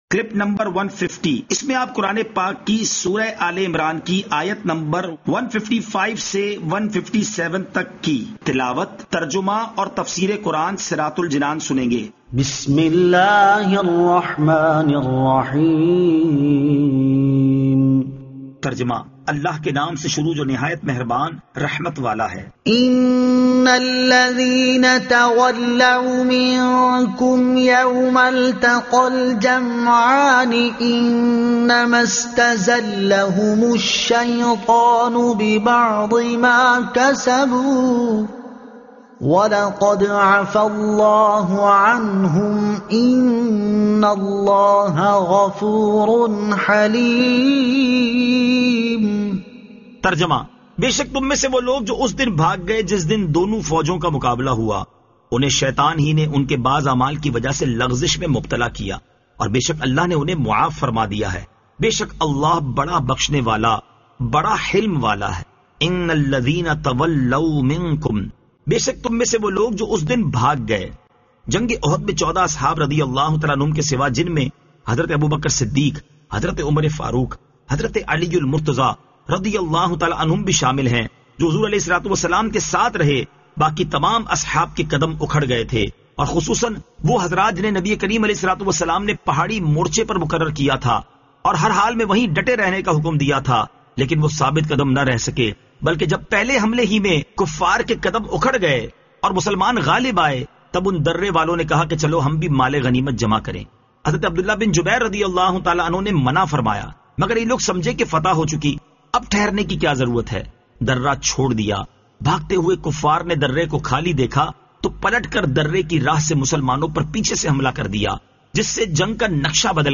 Surah Aal-e-Imran Ayat 155 To 157 Tilawat , Tarjuma , Tafseer